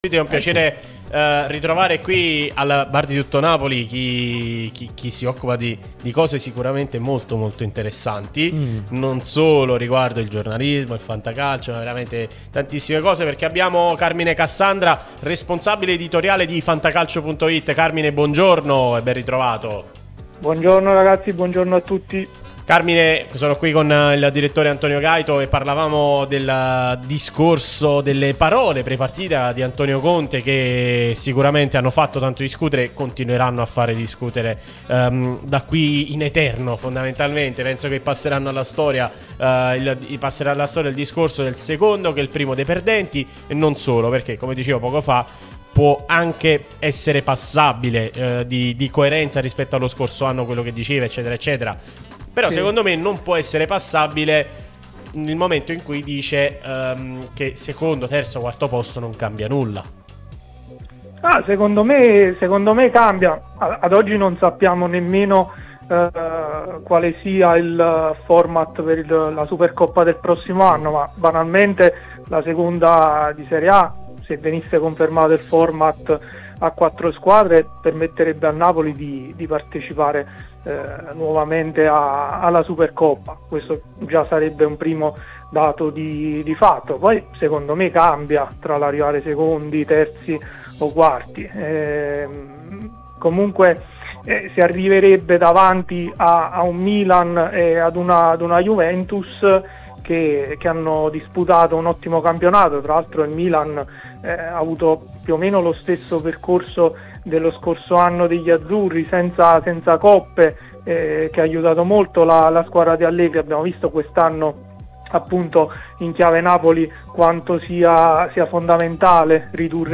Podcast Fantacalcio Napoli